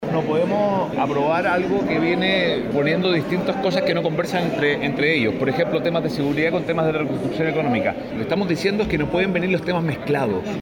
En esa línea, las amenazas de Johannes Kaiser sobre el posible rechazo de algunos elementos fue redoblada por el diputado Pier Karlezi, quien sostuvo “no pueden venir los temas mezclados”.